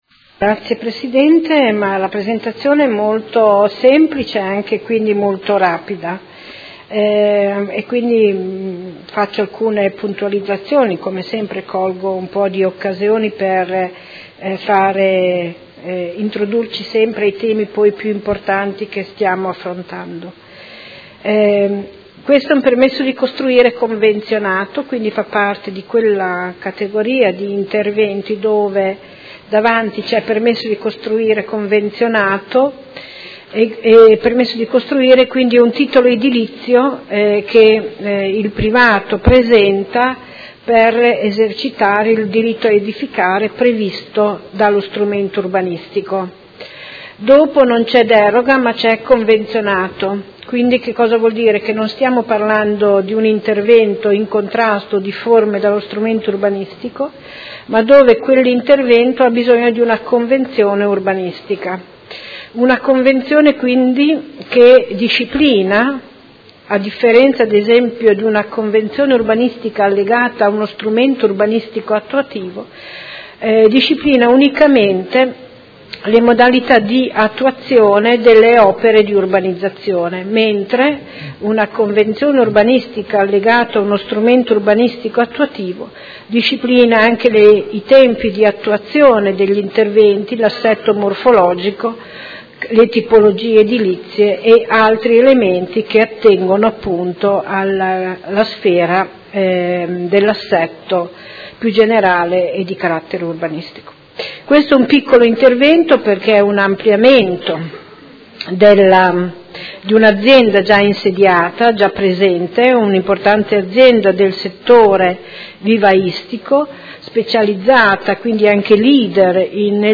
Anna Maria Vandelli — Sito Audio Consiglio Comunale
Seduta del 22/11/2018.